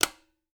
BUTTON_Click_Compressor_Small_02_stereo.wav